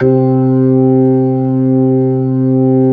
Index of /90_sSampleCDs/AKAI S-Series CD-ROM Sound Library VOL-8/SET#5 ORGAN
HAMMOND   7.wav